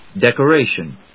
音節dec・o・ra・tion 発音記号・読み方
/dèkəréɪʃən(米国英語), ˌdekɜ:ˈeɪʃʌn(英国英語)/